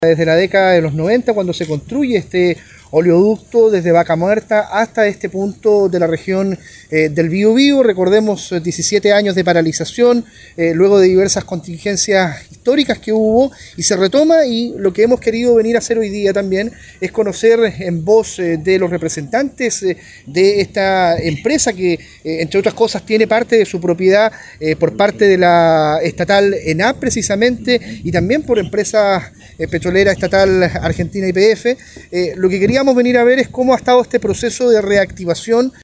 Pacheco recordó la “historia productiva, de trabajo conjunto”, entre Chile y Argentina.